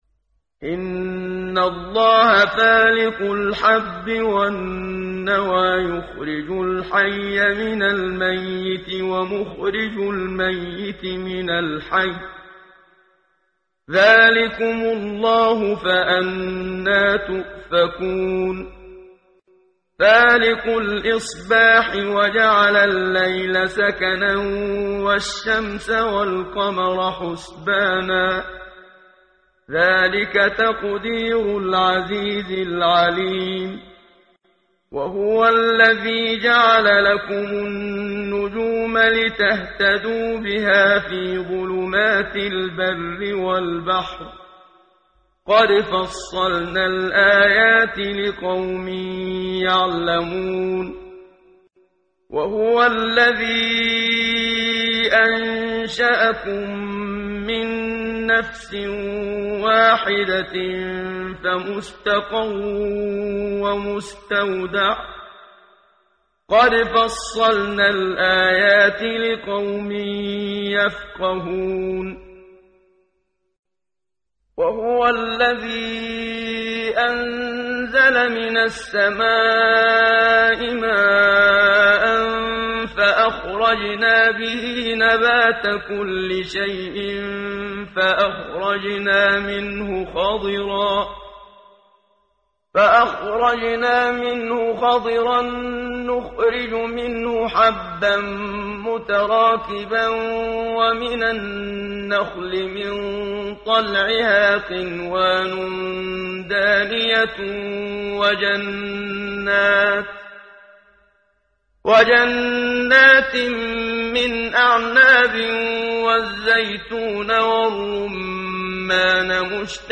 ترتیل صفحه 140 سوره مبارکه انعام (جزء هفتم) از سری مجموعه صفحه ای از نور با صدای استاد محمد صدیق منشاوی